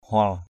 /hʊal/ (d.) đại sảnh.